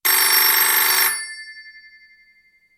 Ara hem de descarregar el so de timbre que trobareu al fitxer
ring.mp3